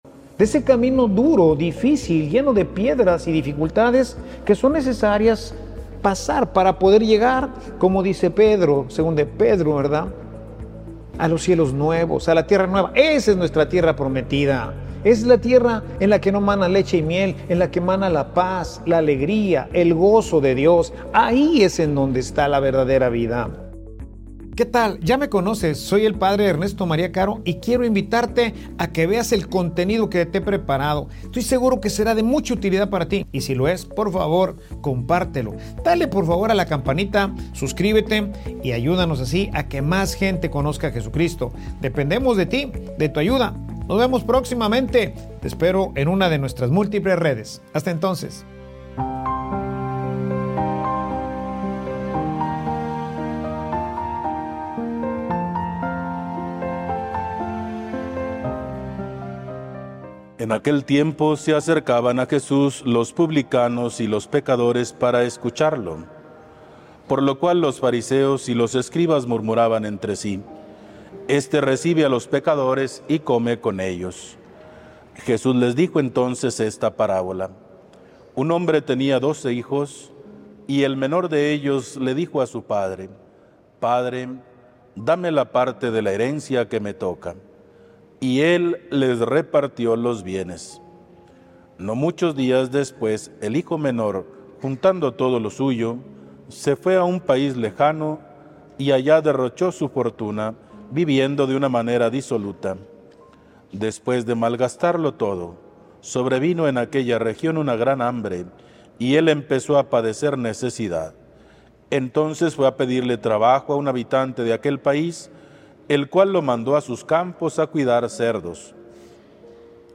Homilia_Un_camino_que_nos_lleva_al_amor.mp3